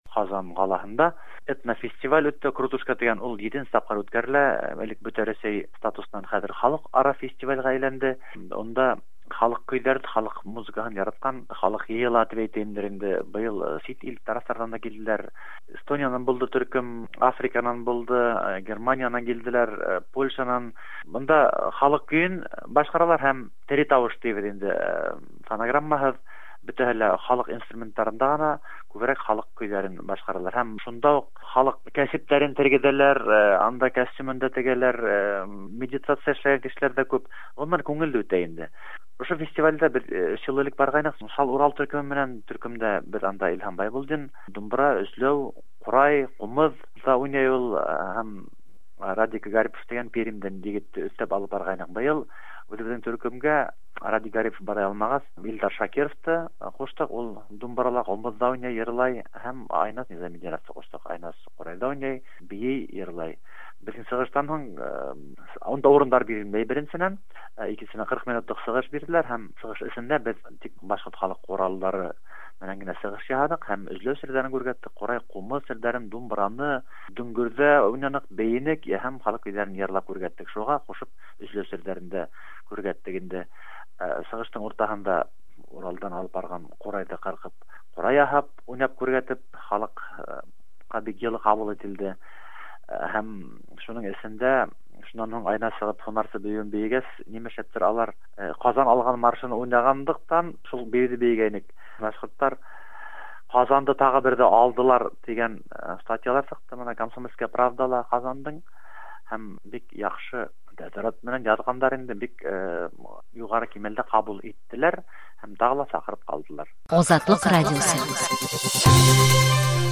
Сәяси вазгыятькә карамастан “Крутушка” халыкара этник фестивалендә быел Эстония һәм Польшадан да төркемнәр катнашкан.
Чал Урал башкорт төркеме Крутушка фестивалендә катнашты